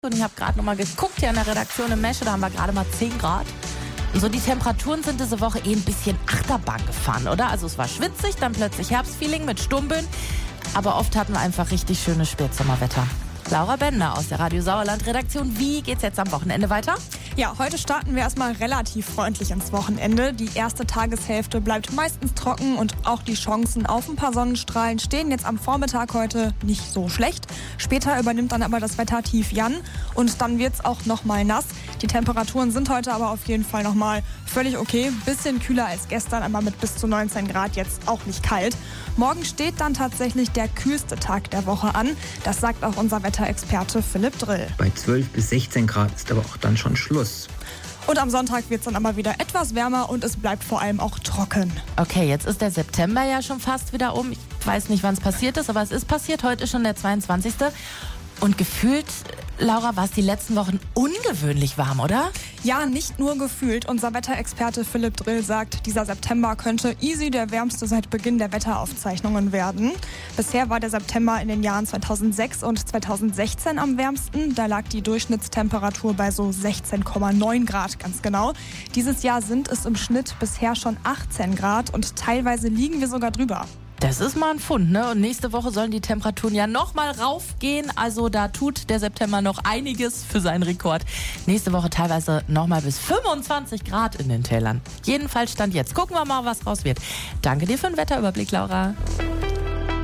mitschnitt-wettertalk.mp3